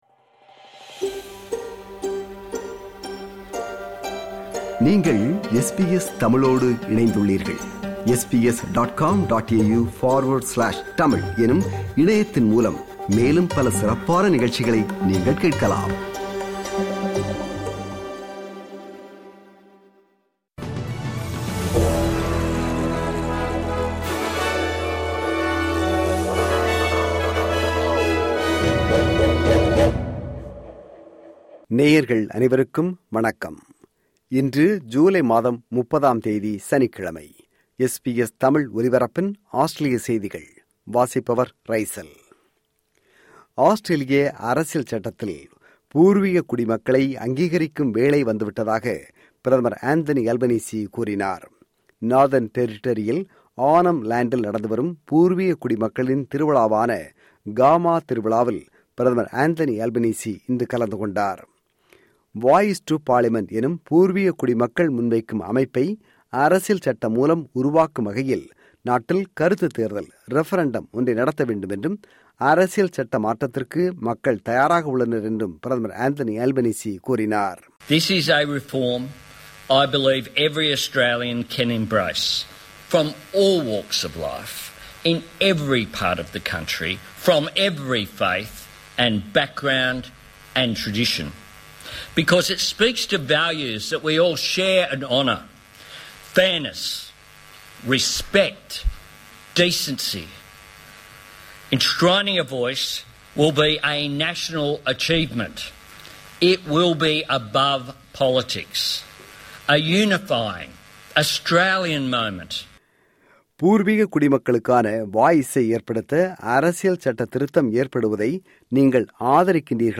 Australian News